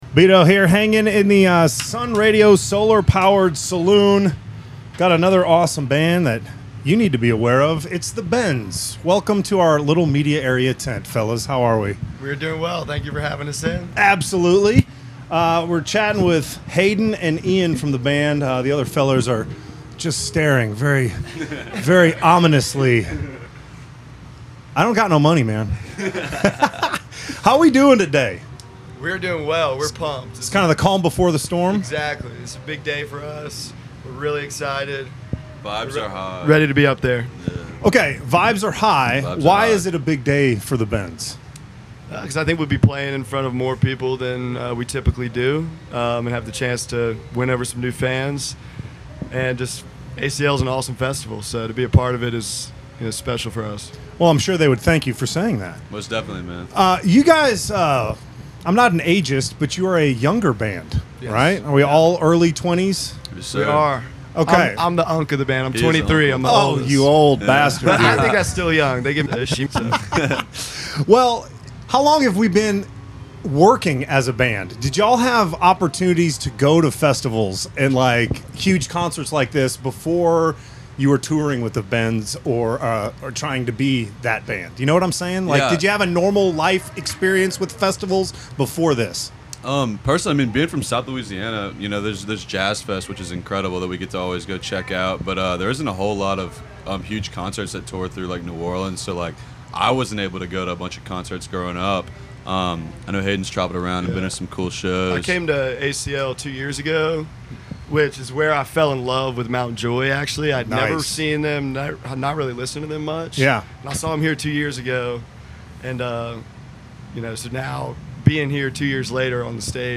Want more interviews from the Sun Radio Solar Powered Saloon?